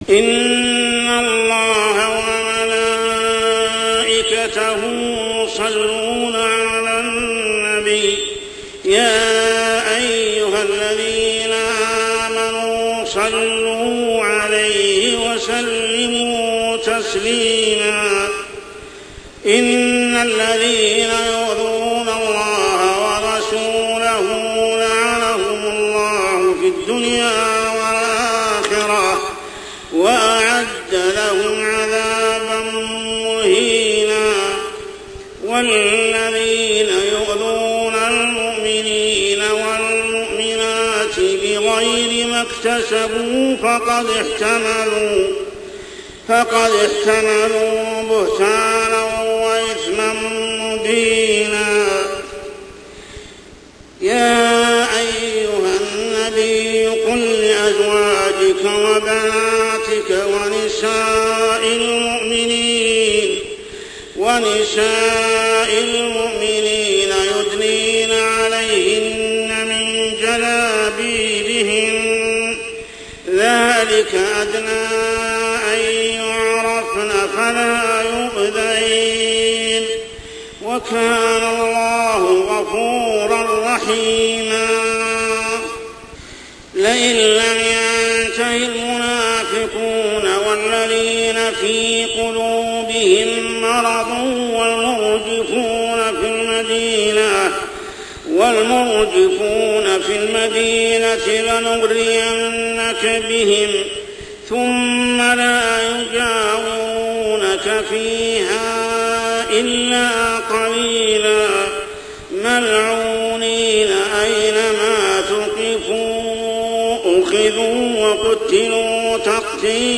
عشائيات شهر رمضان 1426هـ سورة الأحزاب 56-62 | Isha prayer Surah Al-Ahzab > 1426 🕋 > الفروض - تلاوات الحرمين